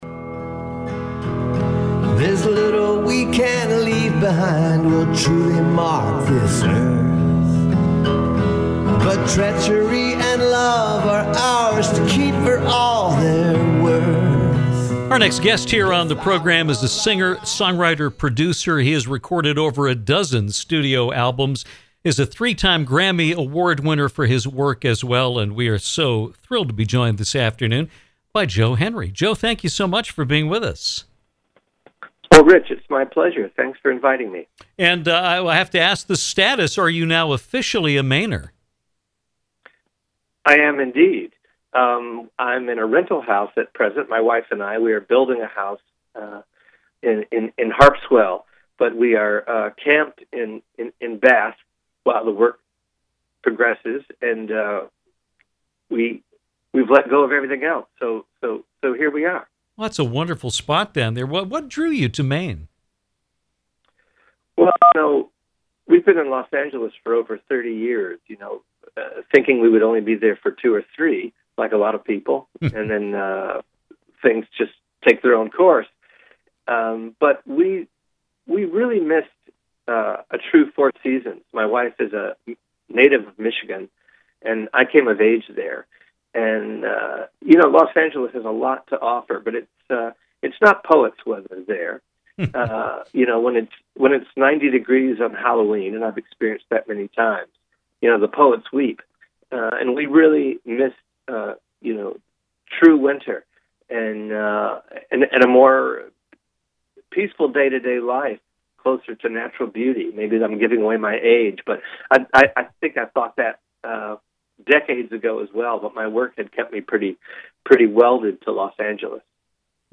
Grammy Award-winning singer-songwriter-producer Joe Henry joined us on today’s Downtown to discuss his career and his move to the state of Maine.